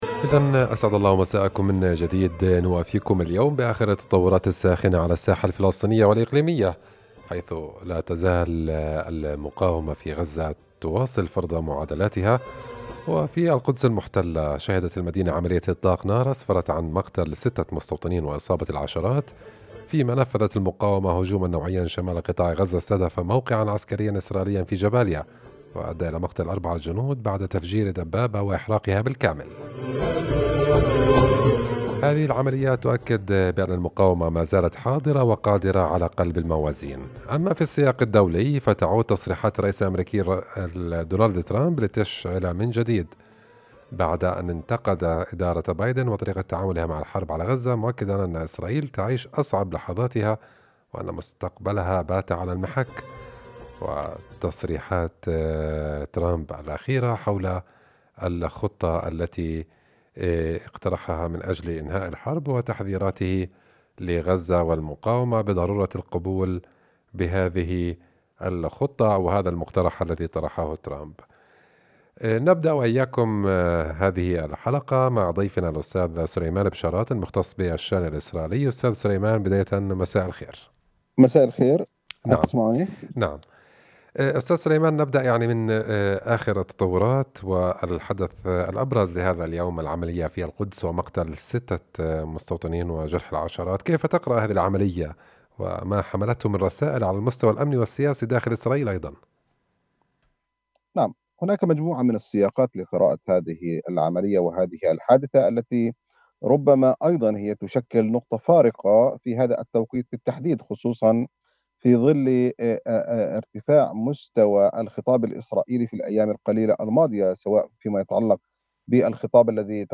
مداخلة إذاعية